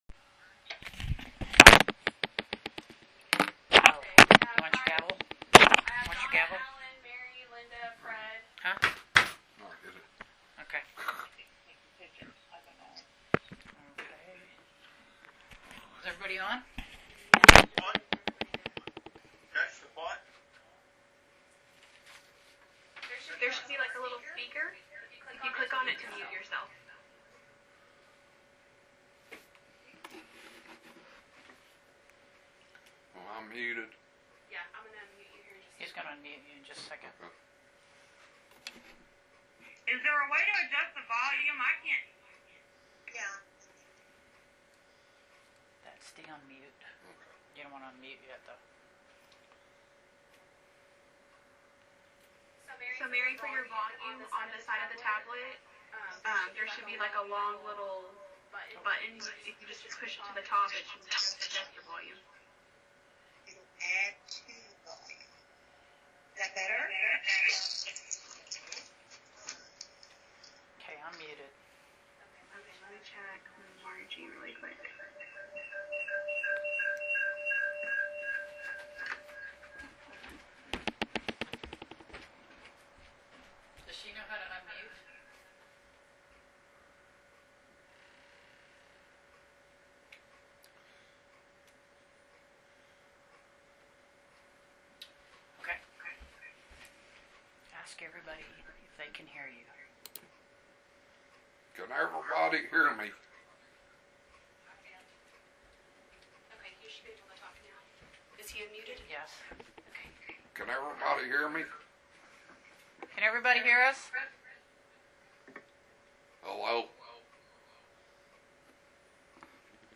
To View Packet Click Here Click here to review the meeting audio recording The City Council of the City of Blue Mound will hold a Regular City Council Meeting Tuesday, April 28, 2020 at 6:30 P.M. The Councilmembers will participate in the meeting remotely via videoconference using the Microsoft Teams App.